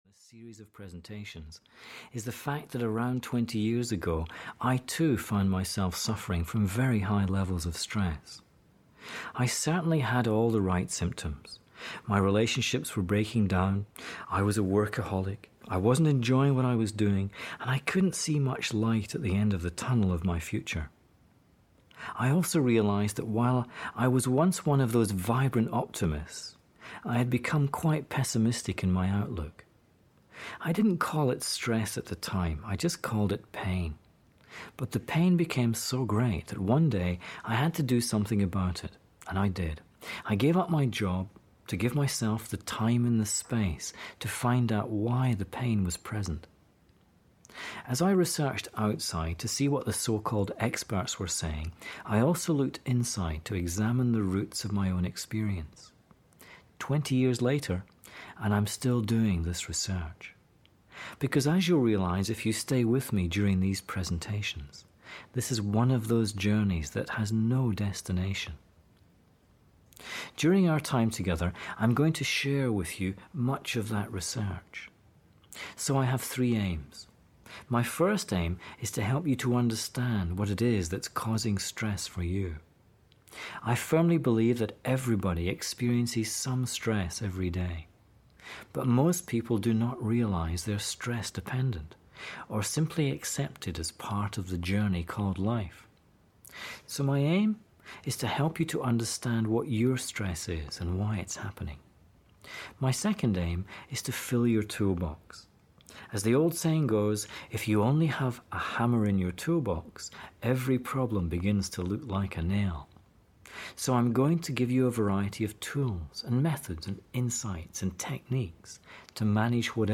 Audio knihaStress Free Living 1 (EN)
Ukázka z knihy